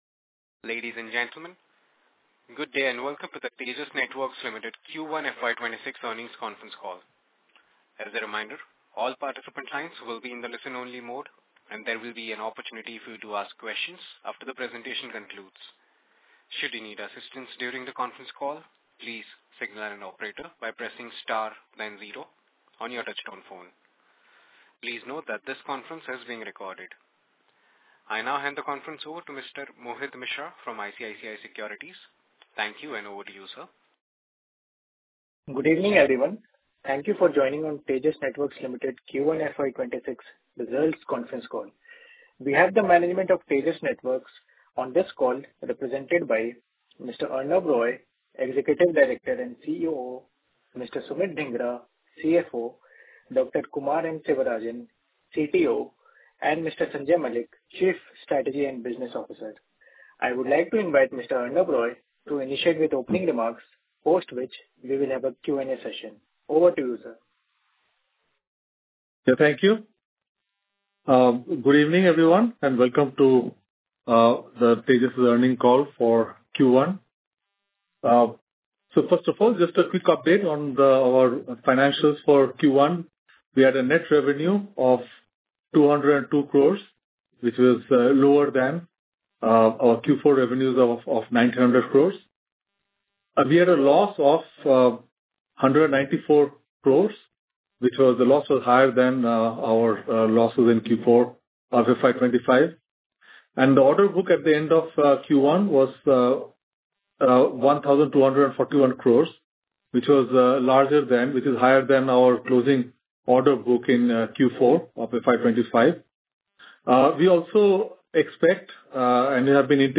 Concalls
Tejas_Q1_FY26_EarningsCall.mp3